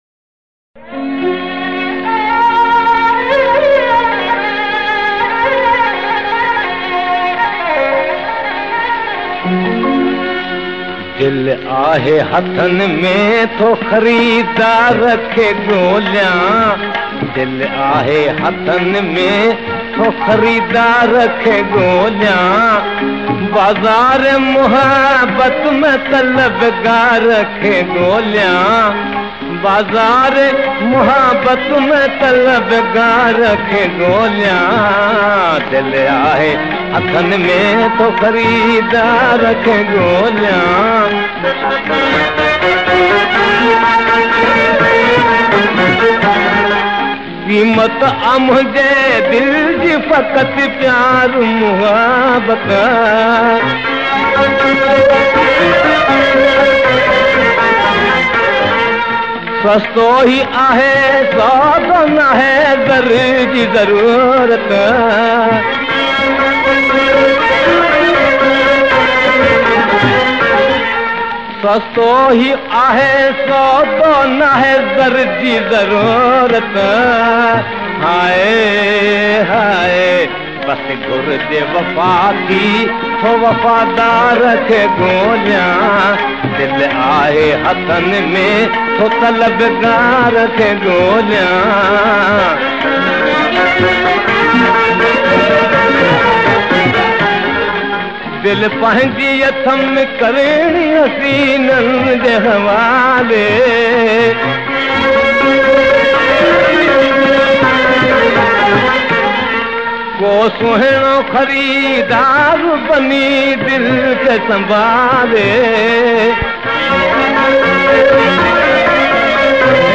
Classic Sindhi Songs in the original voice